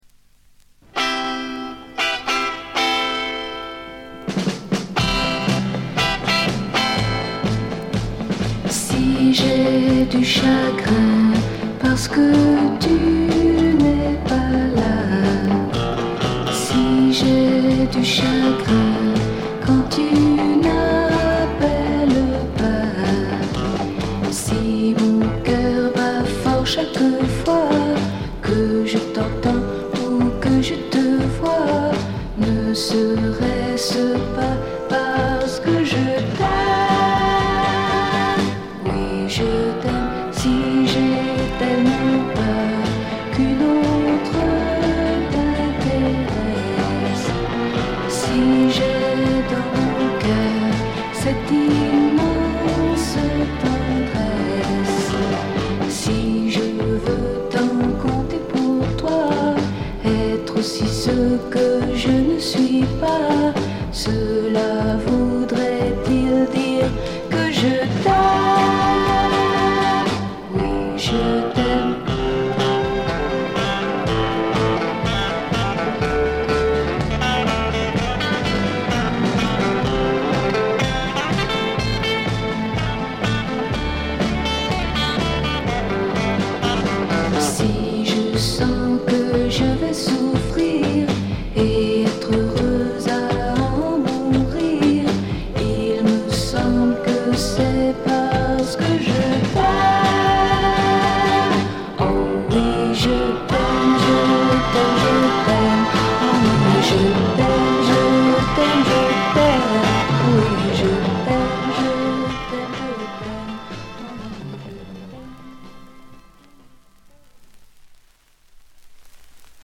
モノラル盤。
試聴曲は現品からの取り込み音源です。